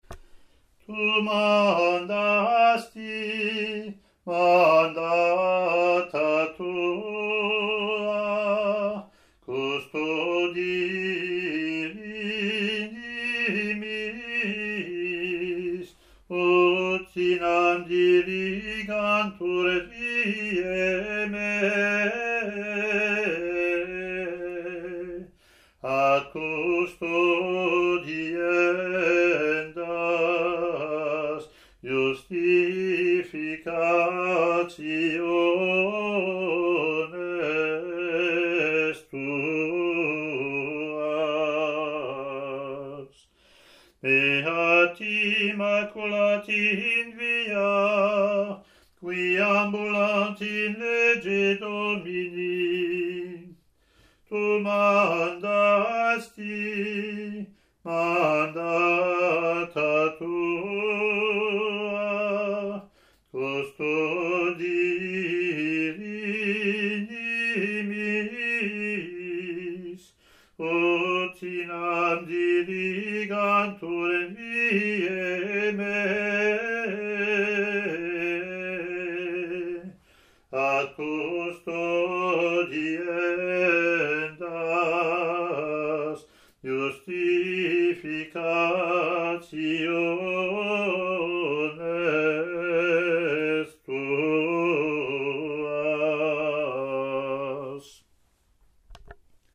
Latin antiphon + verses)